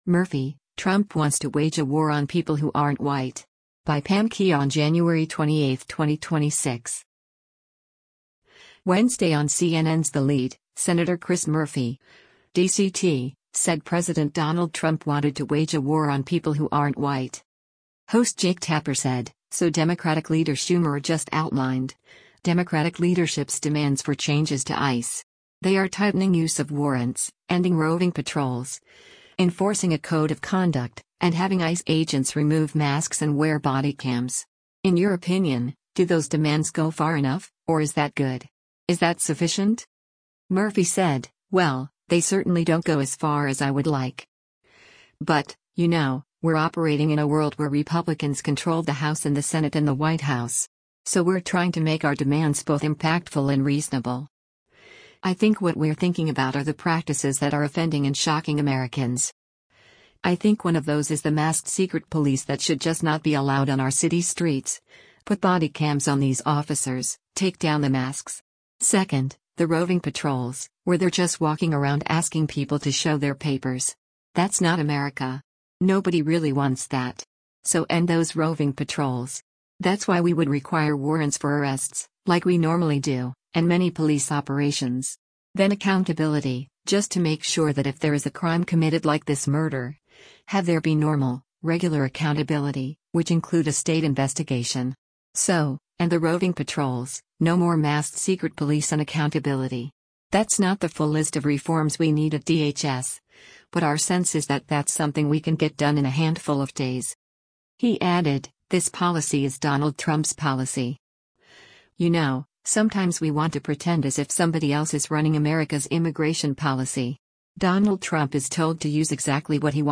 Wednesday on CNN’s “The Lead,” Sen. Chris Murphy (D-CT) said President Donald Trump wanted to “wage a war on people who aren‘t white.”